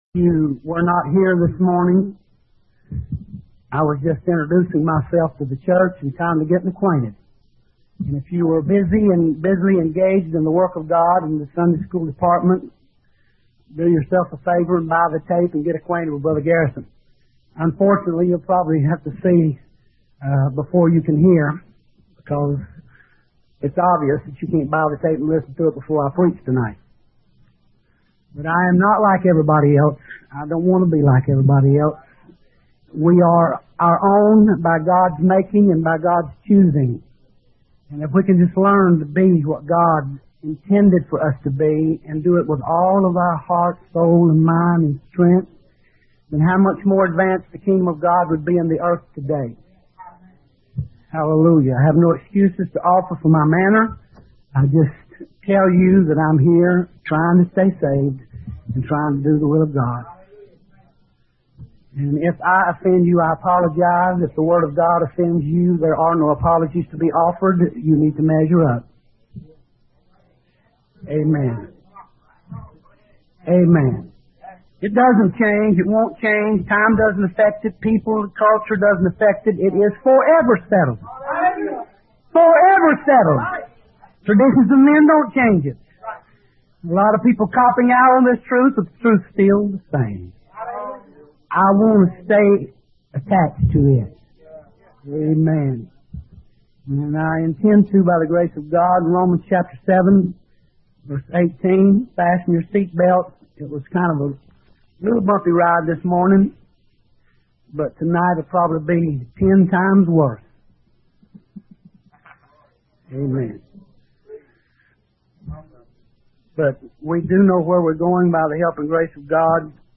Daily Sermon Thread